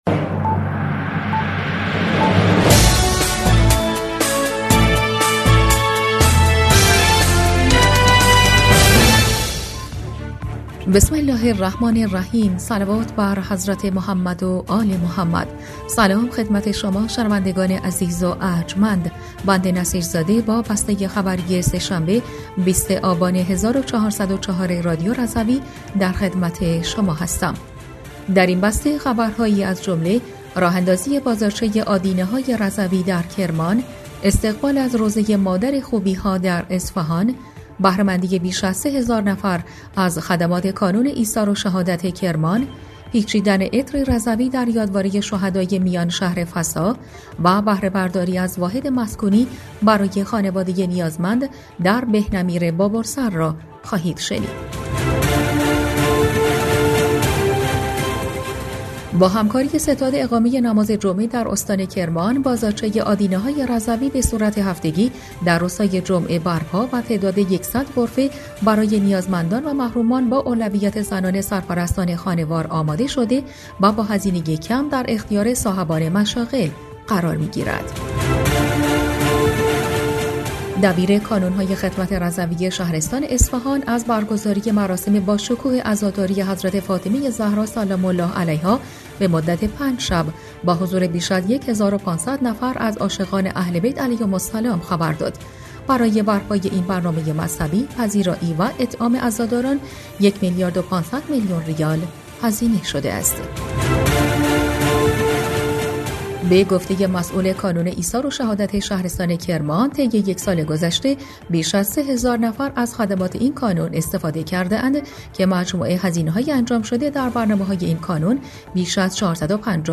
بسته خبری ۲۰ آبان ۱۴۰۴ رادیو رضوی؛